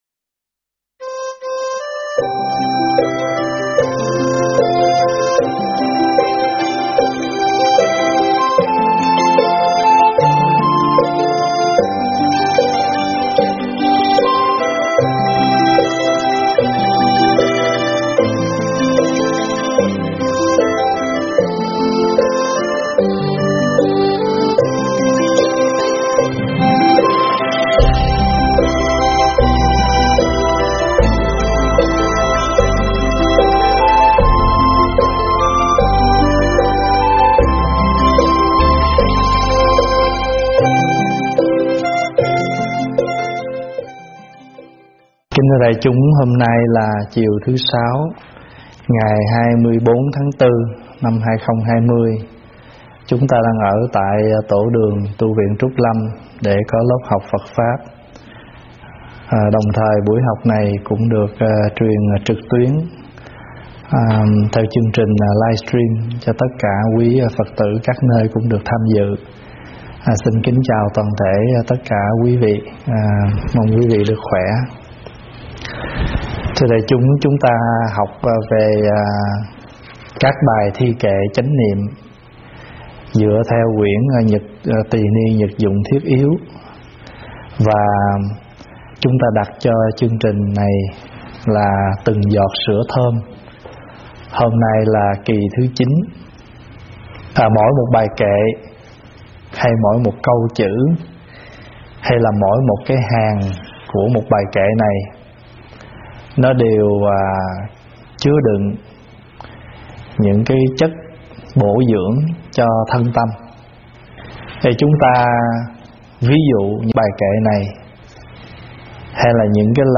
Thuyết pháp Từng Giọt Sữa Thơm 9
giảng Tu Viện Trúc Lâm